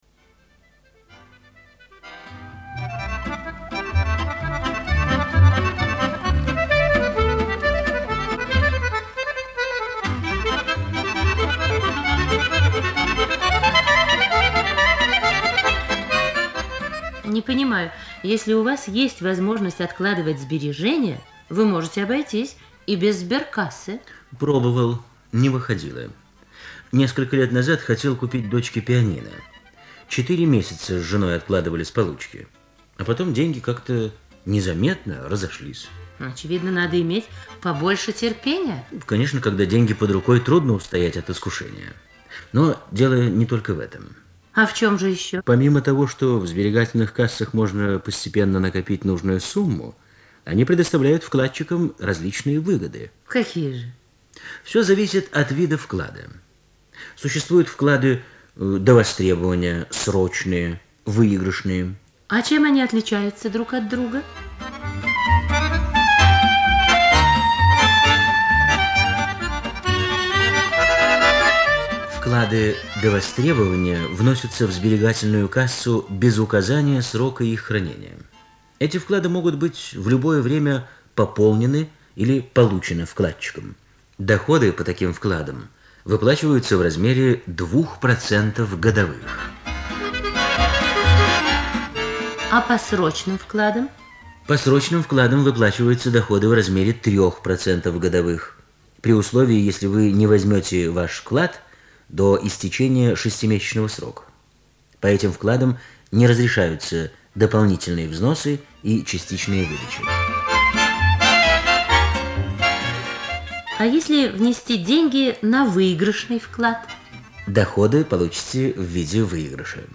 Социальная реклама в СССР